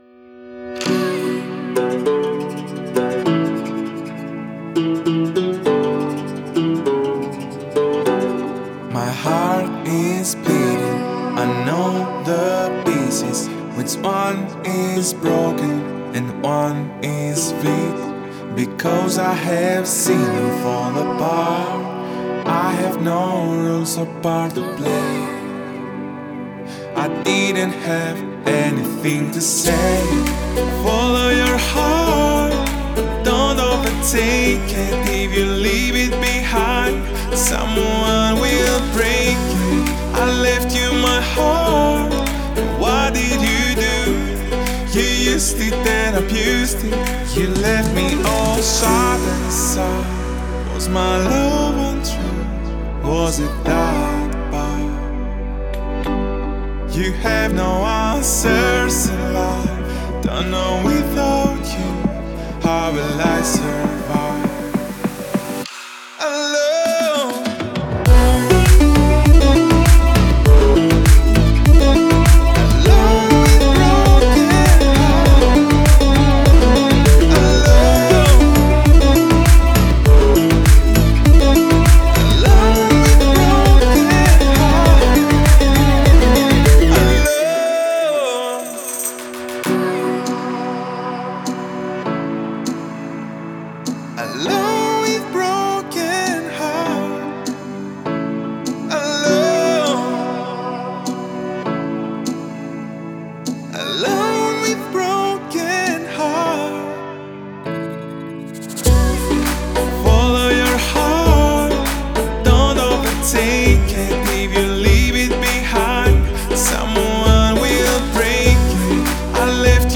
это энергичная электронная композиция